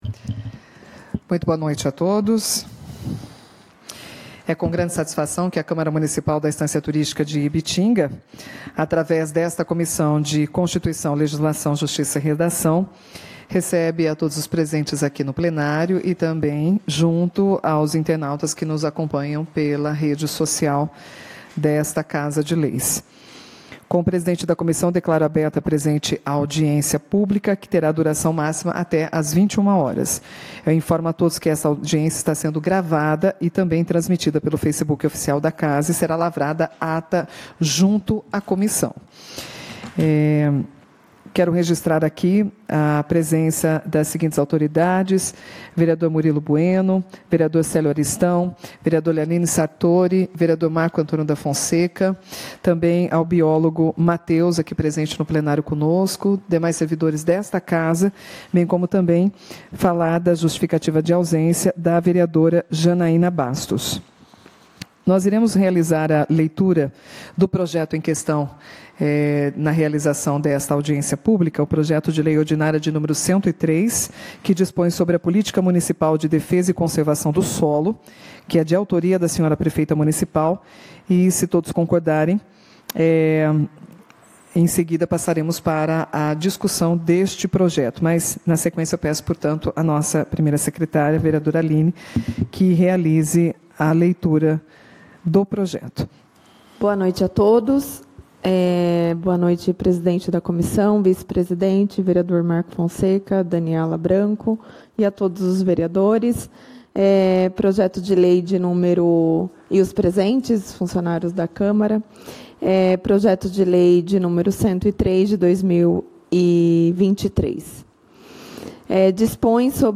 Audiências Públicas